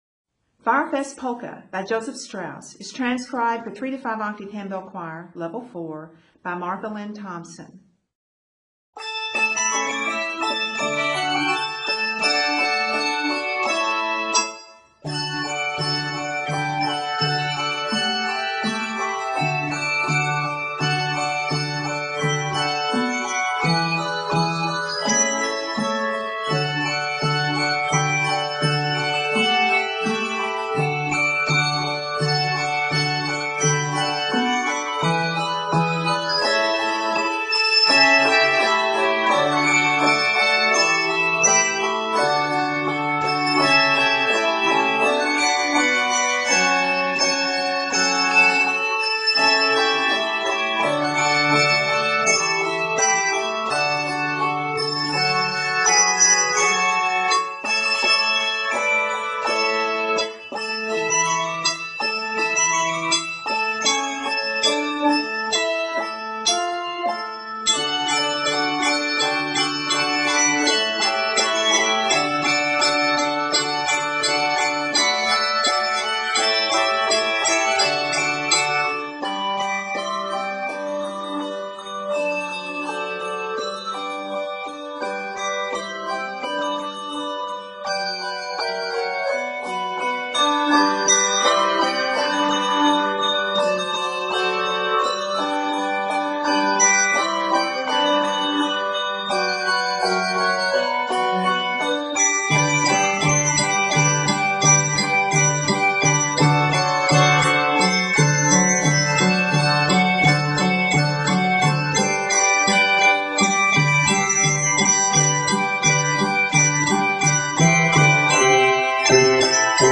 is an exciting transcription of the orchestral piece